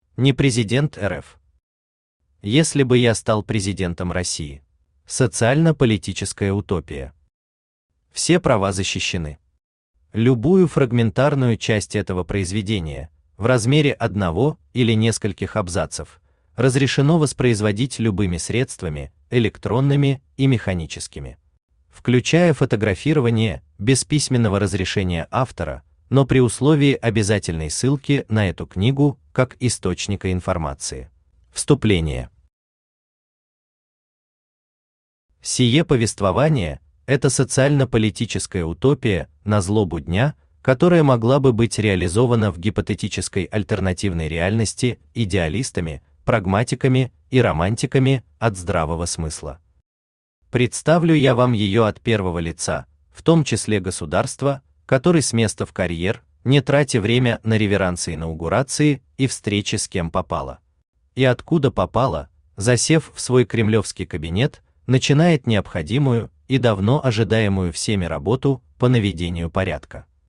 Аудиокнига Если бы я стал президентом России | Библиотека аудиокниг
Aудиокнига Если бы я стал президентом России Автор Непрезидент РФ Читает аудиокнигу Авточтец ЛитРес.